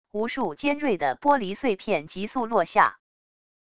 无数尖锐的玻璃碎片疾速落下wav音频